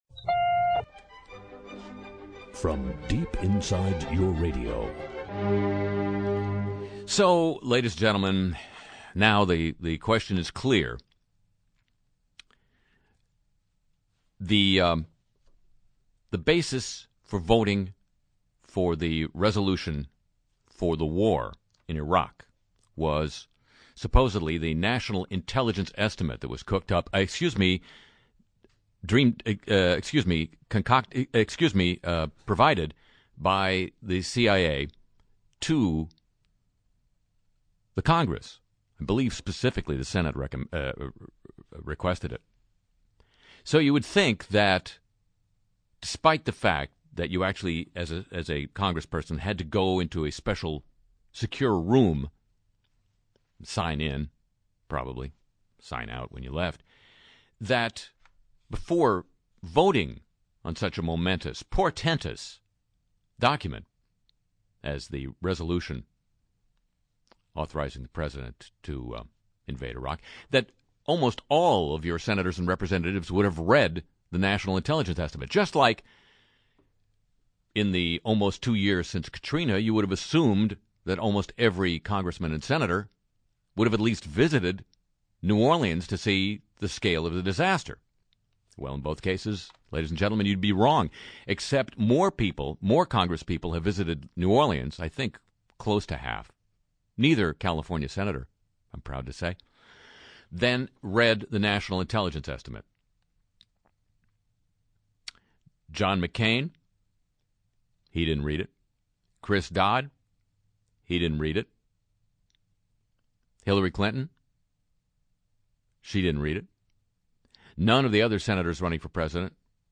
Open Phone Time